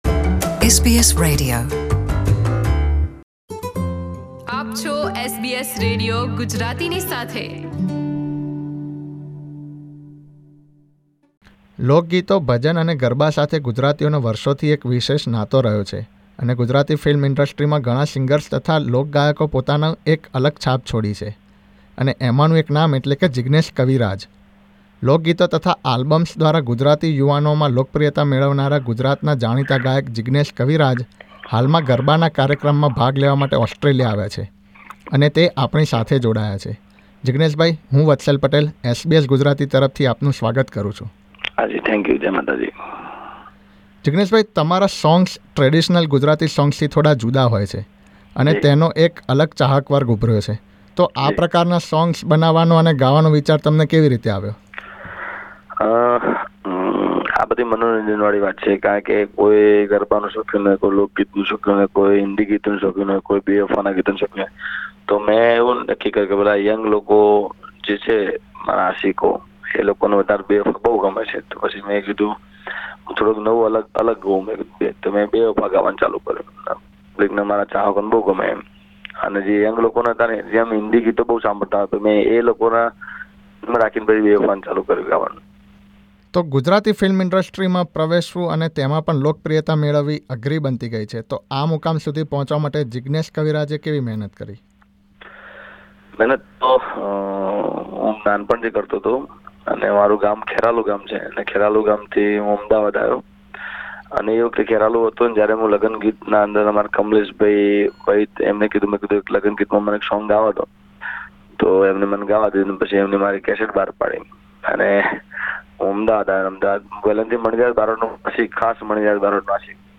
ઓસ્ટ્રેલિયામાં હાલમાં ગરબાના એક કાર્યક્રમમાં આવેલા ગુજરાતી લોકગાયક જીગ્નેશ કવિરાજે SBS Gujarati સાથે પોતાના સંઘર્ષ અને ગુજરાતી ફિલ્મ ઇન્ડ્રસ્ટ્રીમાં એક જુદા જ પ્રકારના ગીતો દ્વારા મેળવેલી લોકપ્રિયતા અંગે પોતાના અનુભવો રજૂ કર્યા.